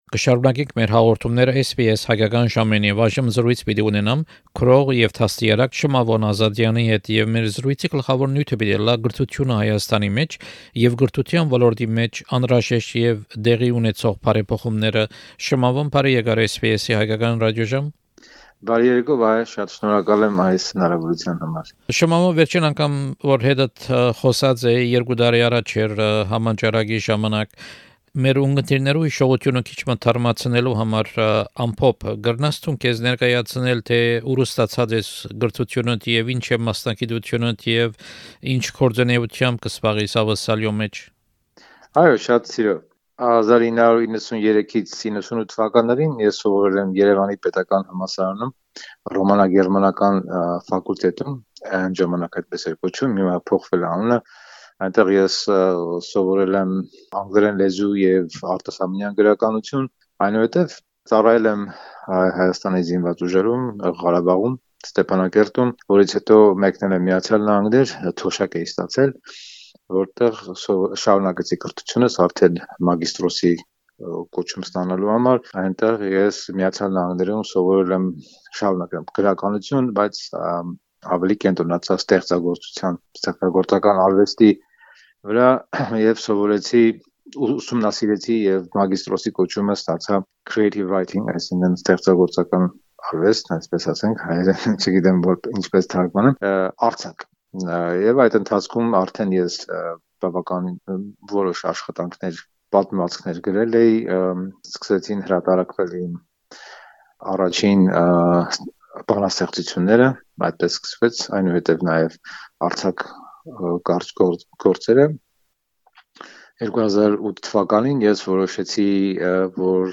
Interview with bilingual